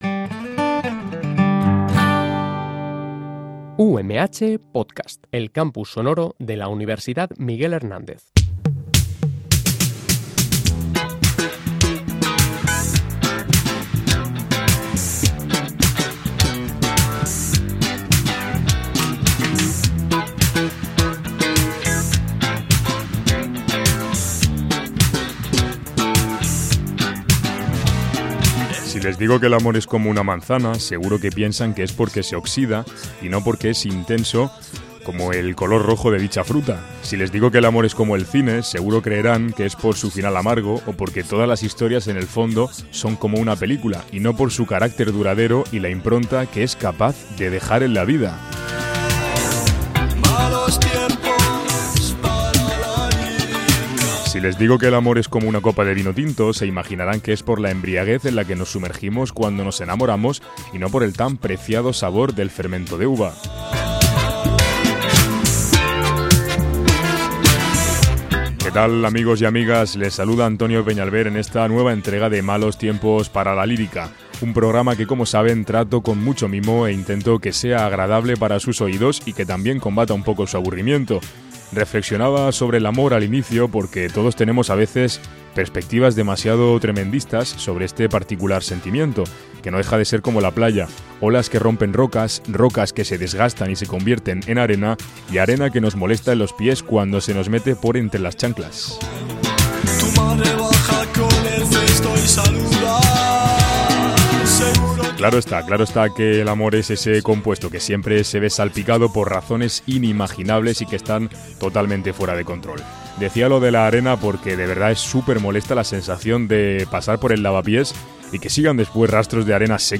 (Español) Entrevista